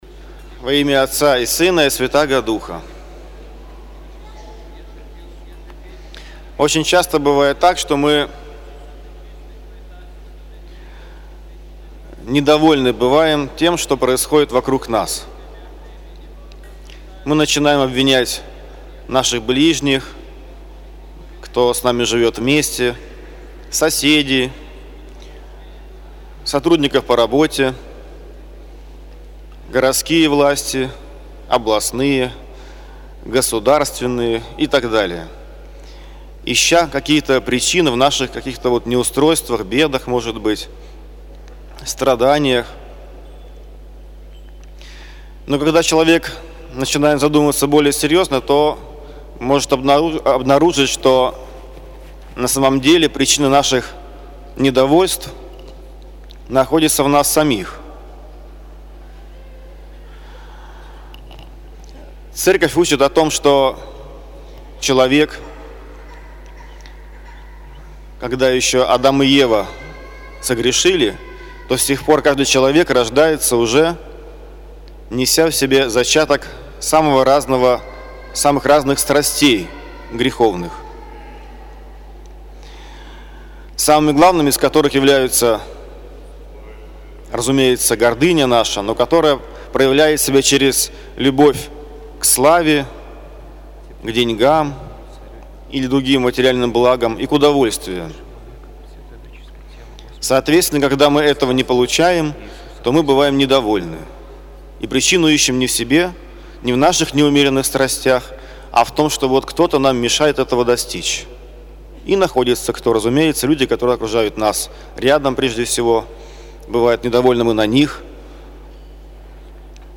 Проповедь на литургии